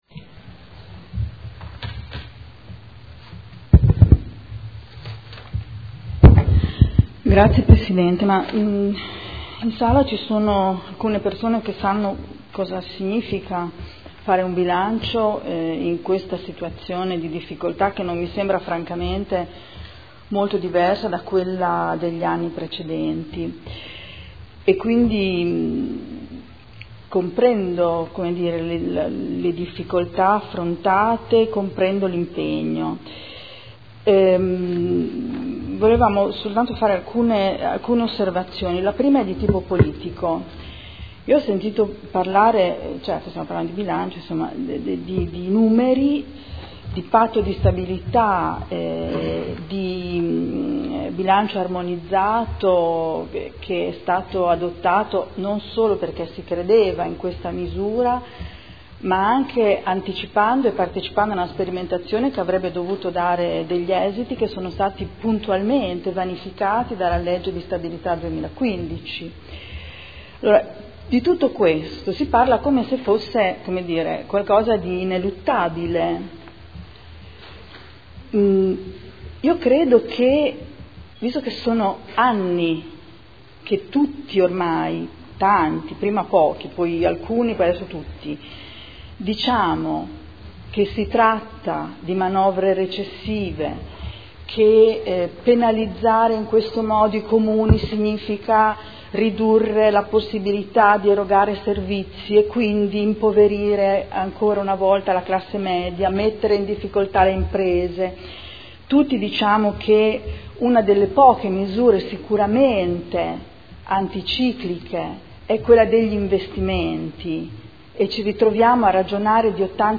Adriana Querzè — Sito Audio Consiglio Comunale
Documento Unico di Programmazione 2015/2019 – Sezione strategica. Dibattito